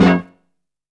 STYLE E STAB.wav